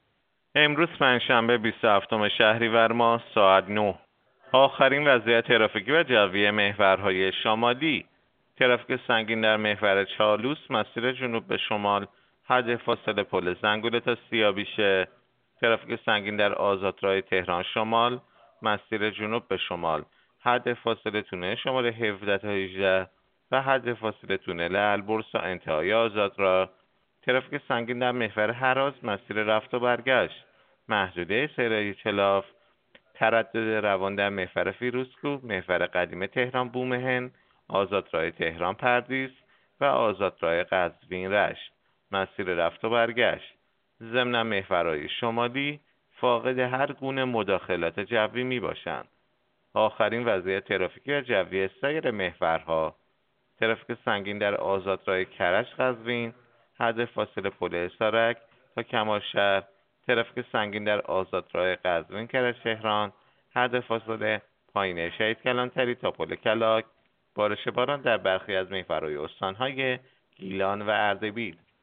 گزارش رادیو اینترنتی از آخرین وضعیت ترافیکی جاده‌ها ساعت ۹ بیست و هفتم شهریور؛